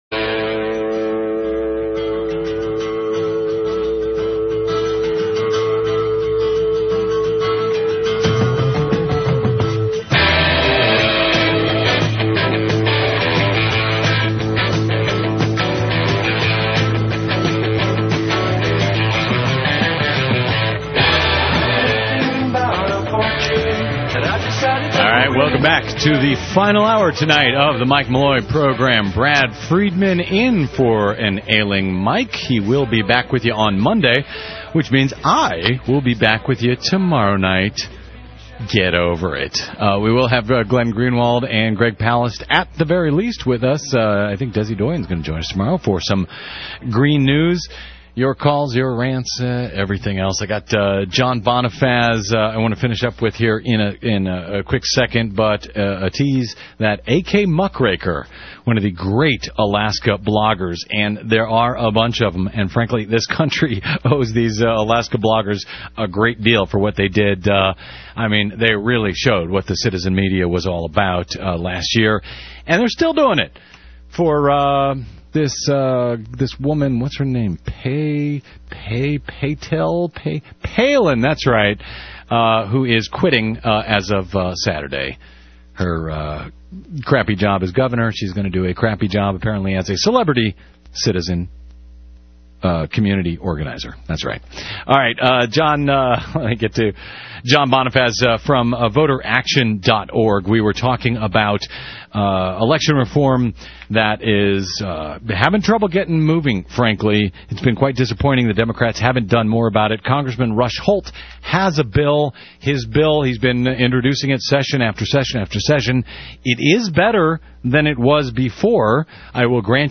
It is excellent radio.